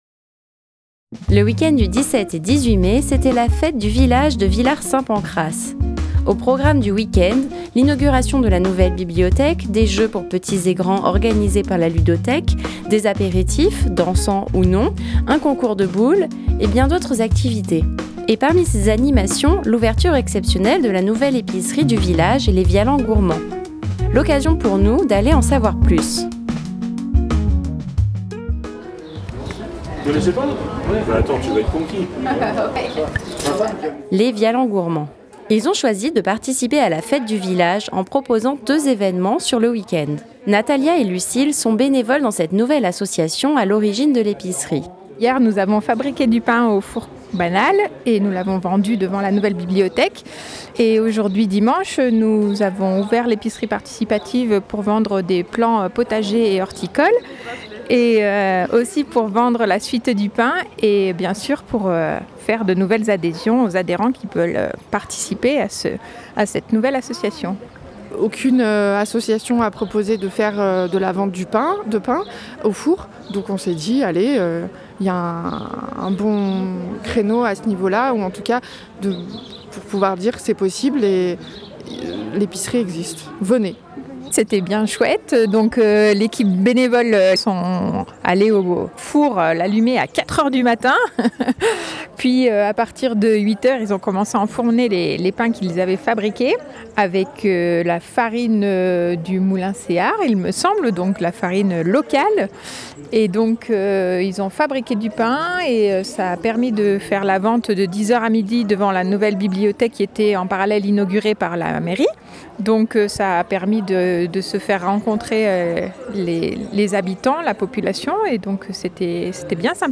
Les 17 et 18 mai, c'était la fête du village de Villard-Saint Pancrace.
Vialans Gourmands - Fête de Villard Saint Pancrace.mp3 (16.51 Mo)